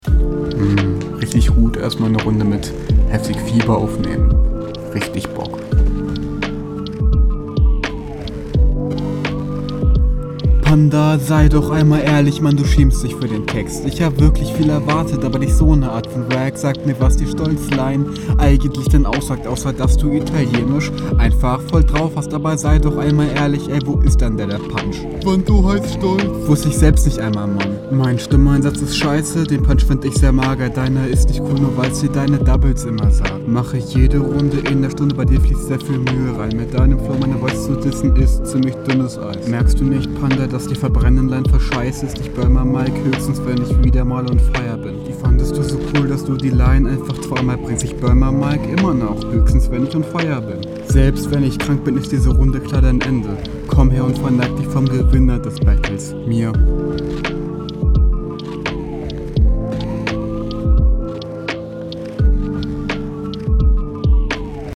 Direkt fällt auf, dass sehr "dünn" klingst vom Stimmsatz im …